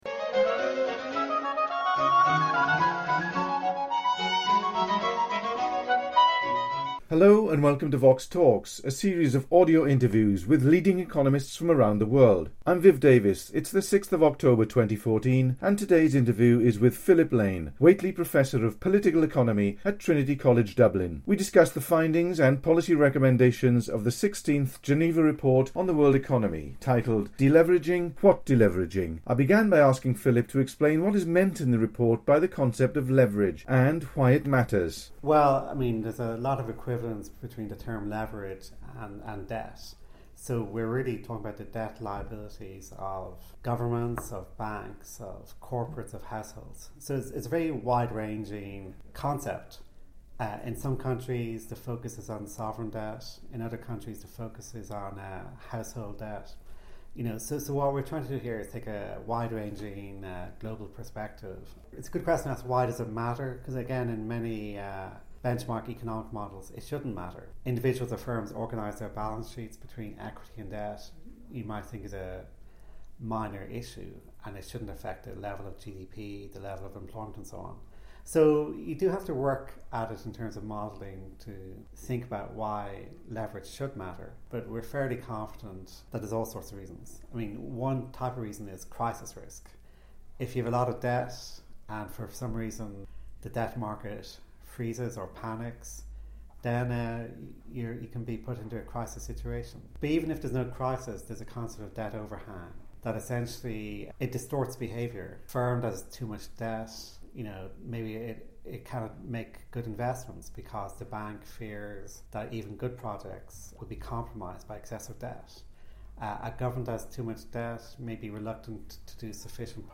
Philip Lane interviewed